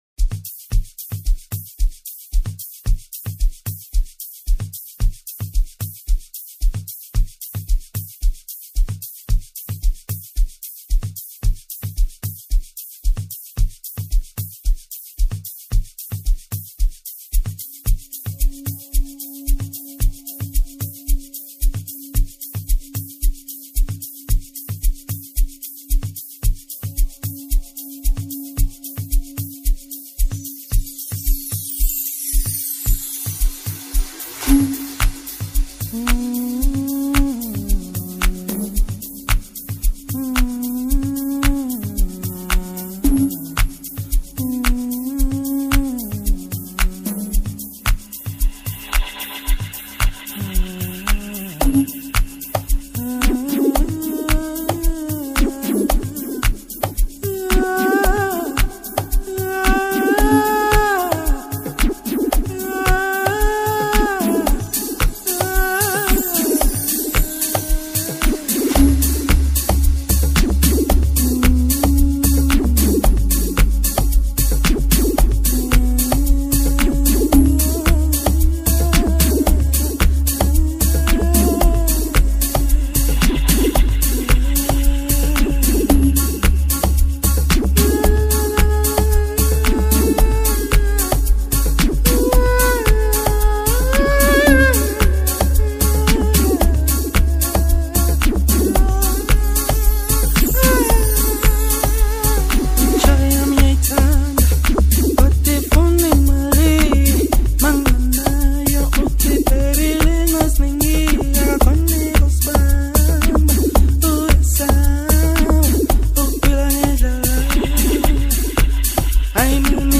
This hit begins with a bass trap